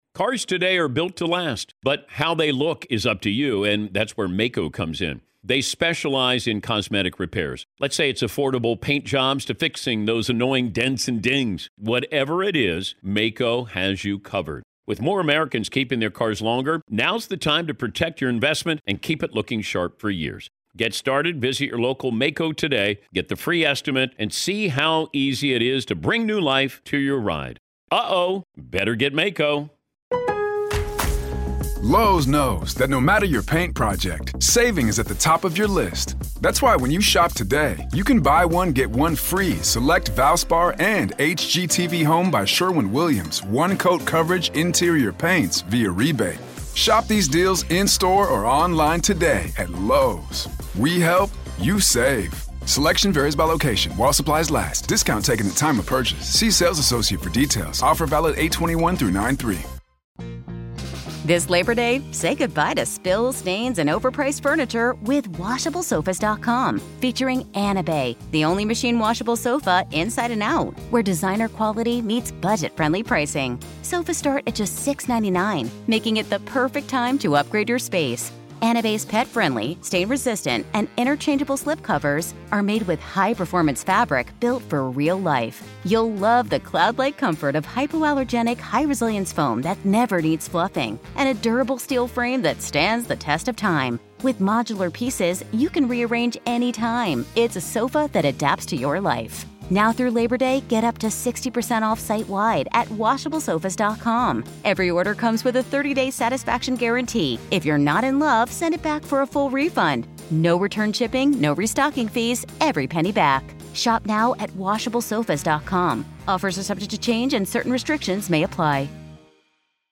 RAW AUDIO-Florida Dentist Sentenced to Life in Prison for Masterminding Murder, Family Matriarch Faces Charges